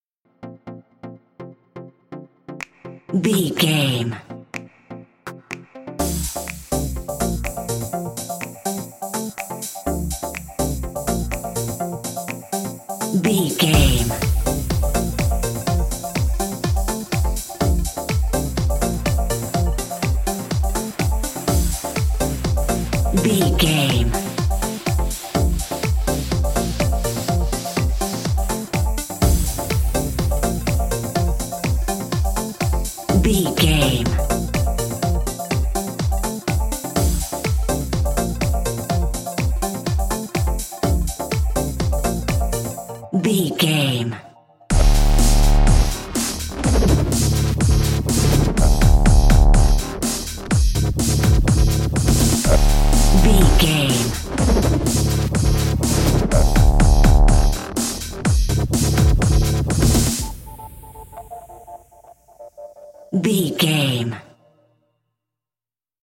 Epic / Action
Fast paced
Aeolian/Minor
D
aggressive
powerful
dark
driving
energetic
piano
drum machine
synthesiser
breakbeat
synth leads
synth bass